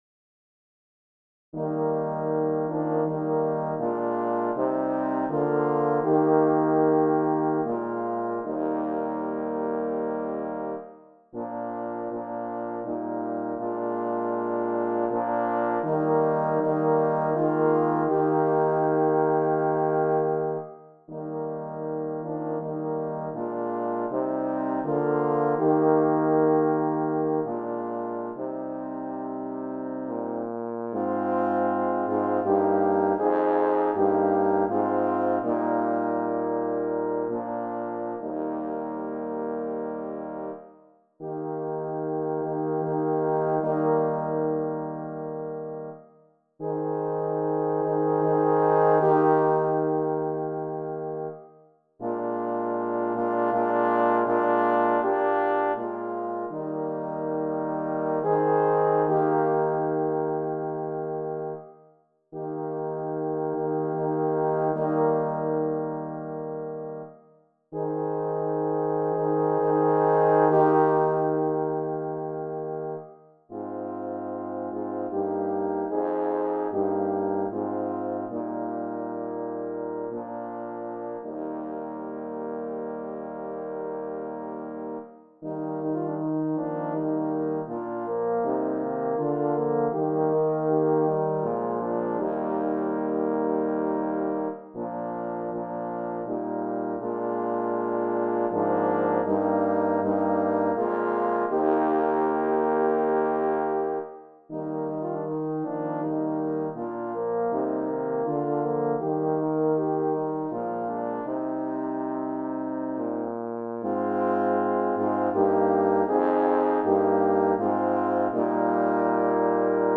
I have arranged it for horn quartet.
HYMN MUSIC